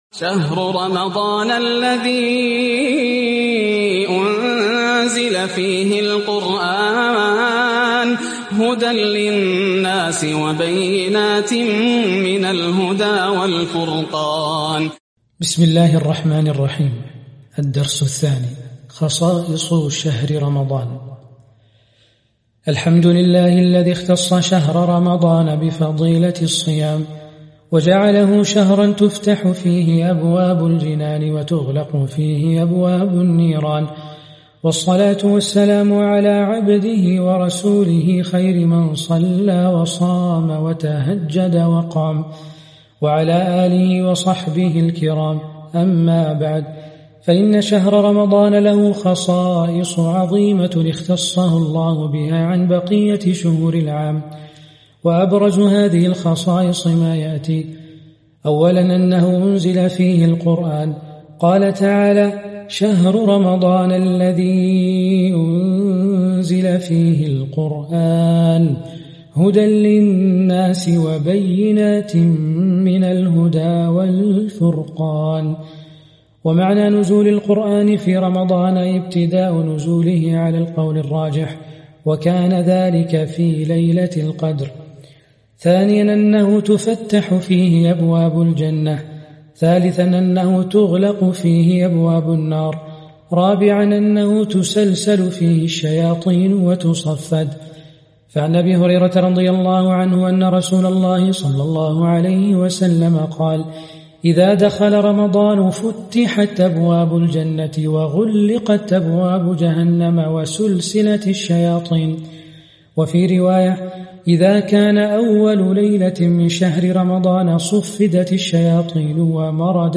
عنوان المادة خصائص شهر رمضان - القراءة الصوتية لكتاب عقود الجمان في دروس شهر رمضان ح3